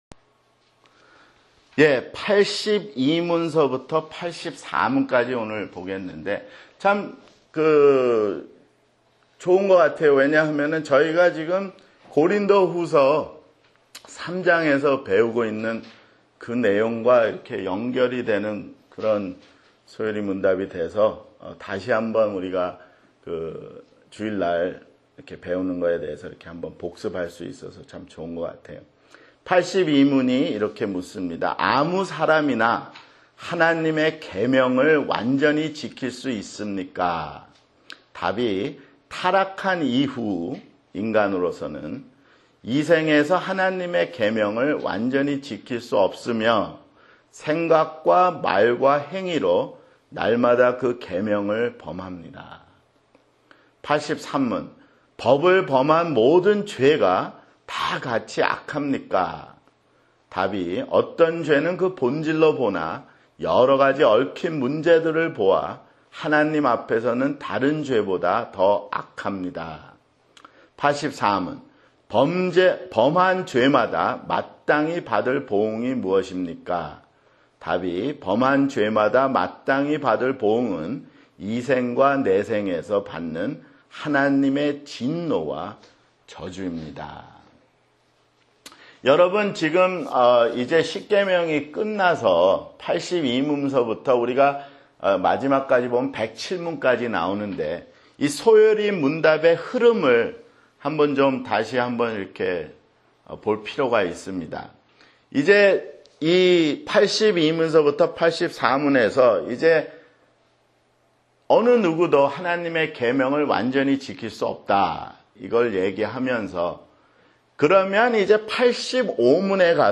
[성경공부] 소요리문답 (65)